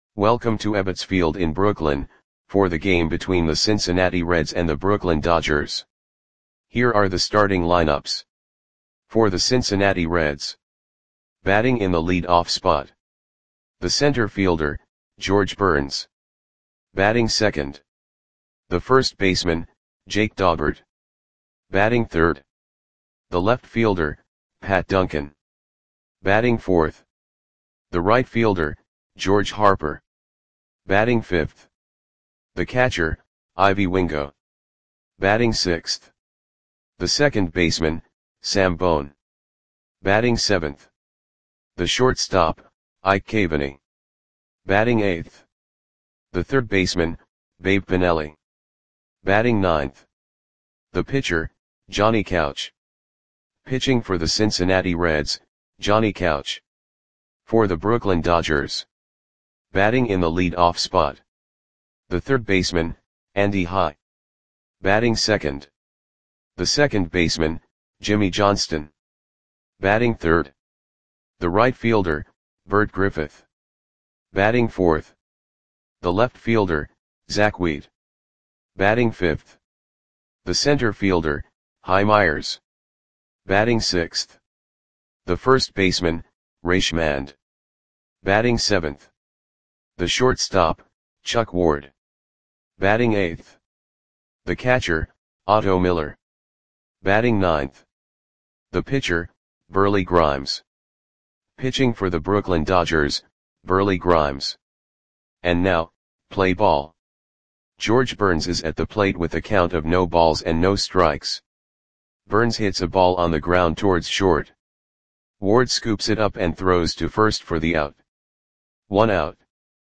Audio Play-by-Play for Brooklyn Dodgers on June 9, 1922
Click the button below to listen to the audio play-by-play.